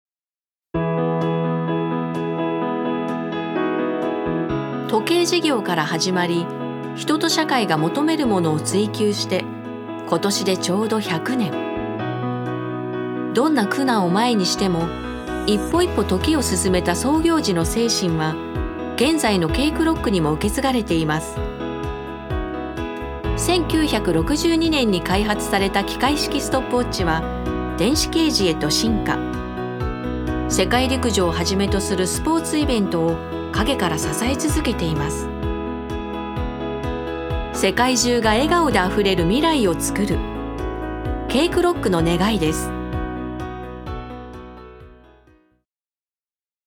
女性タレント
ナレーション２